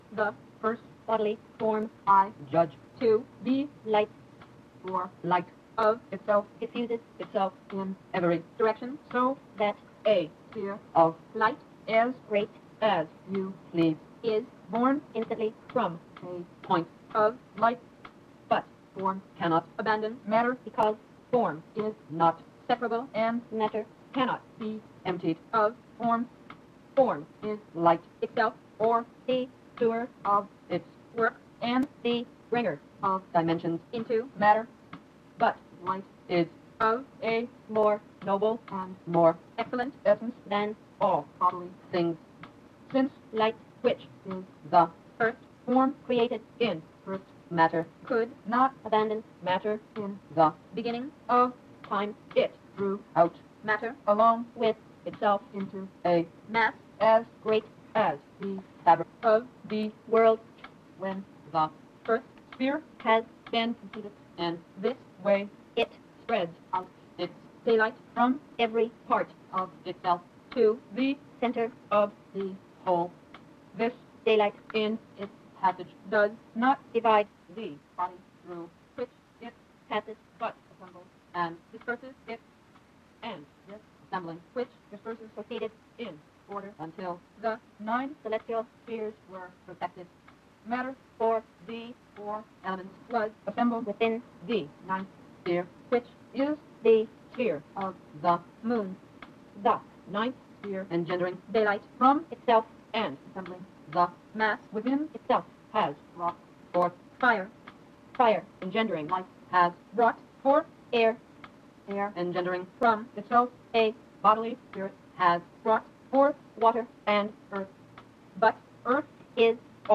In the 10 minute conclusion, six women read a text of Robert Grosseteste (1225-1228) each word is spoken for 1 second.
The second, completely silent part of the movie with pictures is here sped up by a factor 20 (!) (otherwise it is completely unbearable to watch). The last part is sped up here by a factor 2 so that the 10 minutes (also unbearable) have been sped up to 5 minutes. Every word is spoken now in exactly half a second (rather than 1 second of the original).